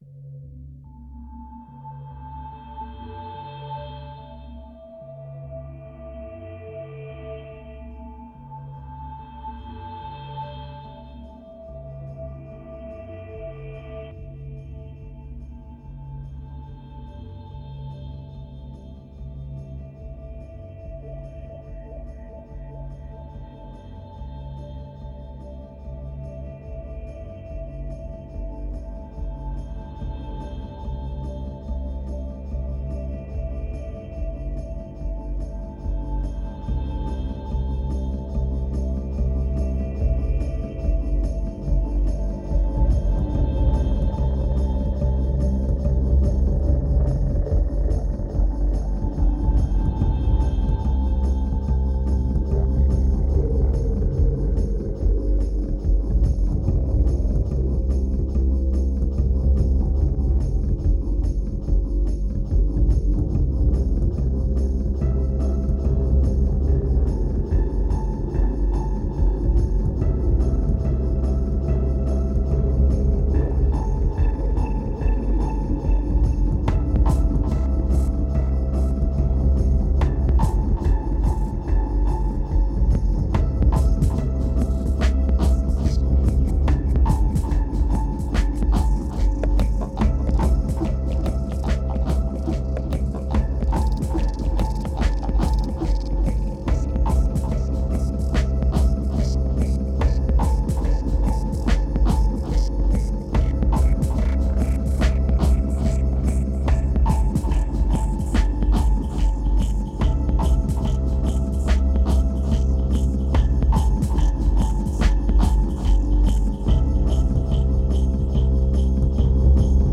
2816📈 - 4%🤔 - 72BPM🔊 - 2010-12-17📅 - -236🌟